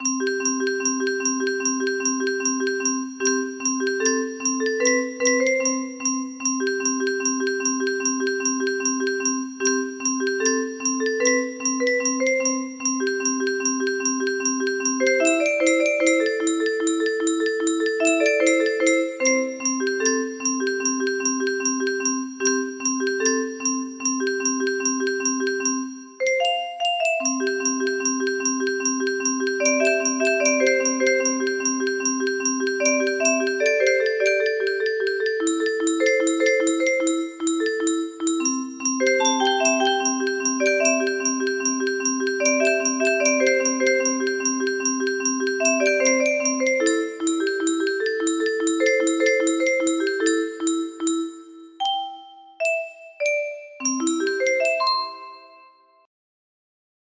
Modified for Grand Illusions 30 note.